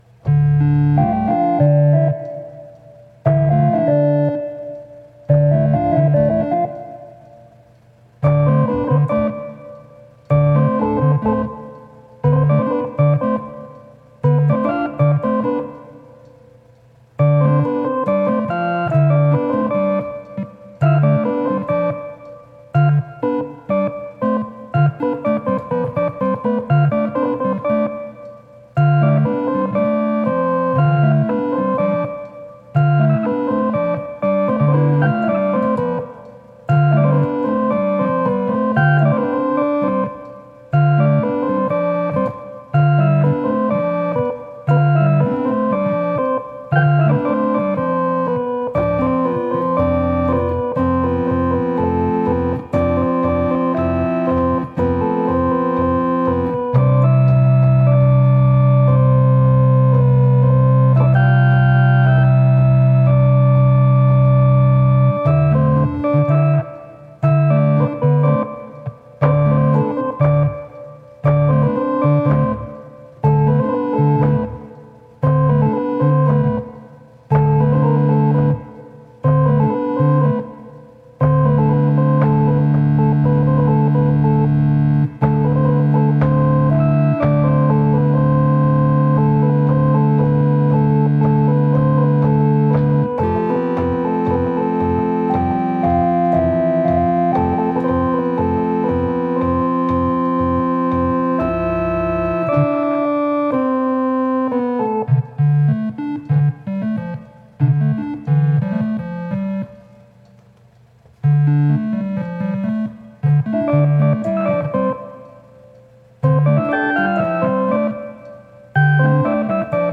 This was my 9th day recording on this found organ.
day09_instrumental_2.mp3